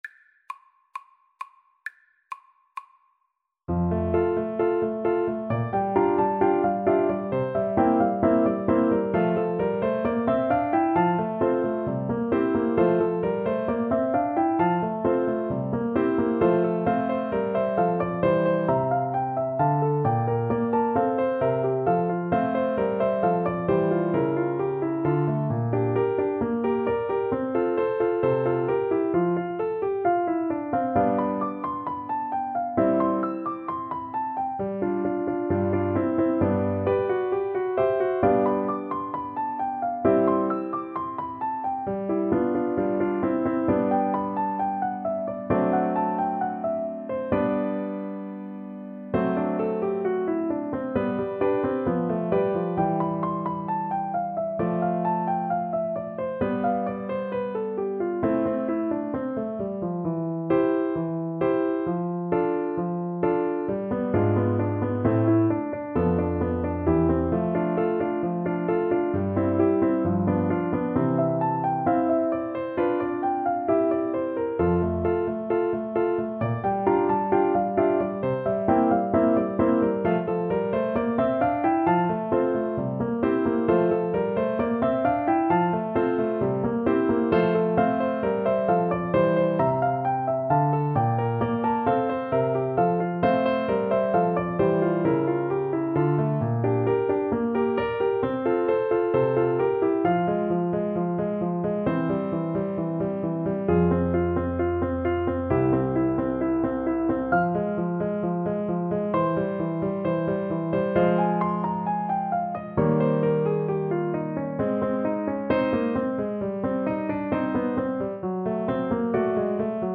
Play (or use space bar on your keyboard) Pause Music Playalong - Piano Accompaniment Playalong Band Accompaniment not yet available transpose reset tempo print settings full screen
F major (Sounding Pitch) (View more F major Music for Flute )
=132 Allegro assai (View more music marked Allegro)
Classical (View more Classical Flute Music)